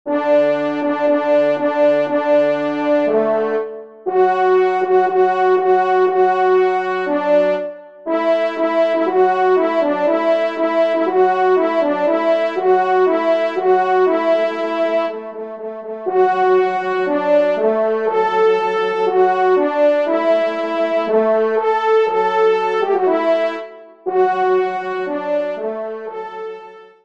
1ère Trompe